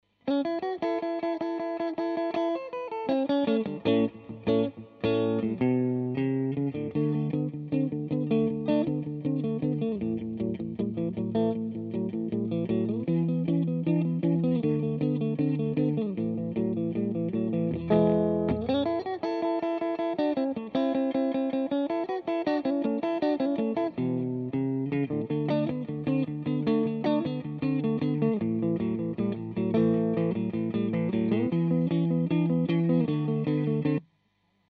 Both mics are touching the cab fret just off centre of the Greenback, a 55Hz jobbie.
The clip is being played out of a 2496 card to the amp.
The levels can be seen attached and in fact average around -13dBFS with a 'clang' at -9.5dBFS max.
The right hand mic was the crap dymo, so crap I think that I shall rig another test with my shiny new Prodipe TT1.
The dymo just sounded 'dull' to me.